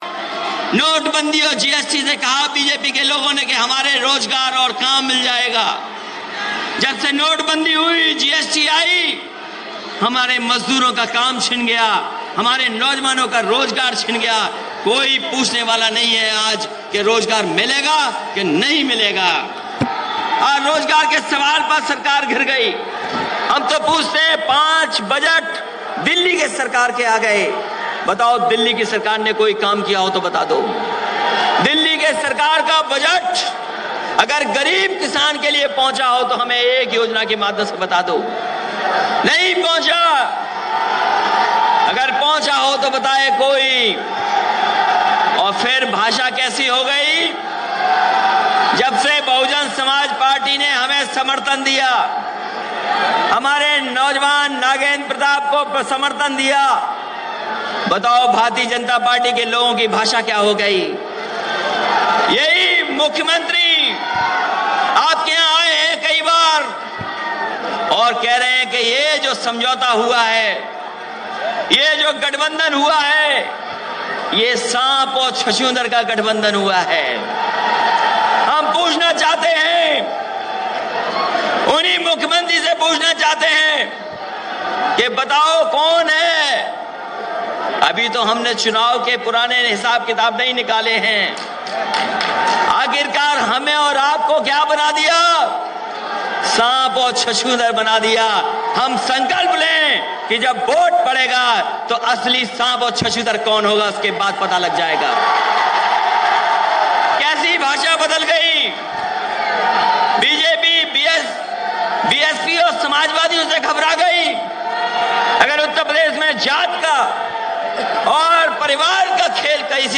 News Report / बीजेपी की ताबूत में आख़िरी कील ठोकेंगे - समाजवादी पार्टी के मुखिया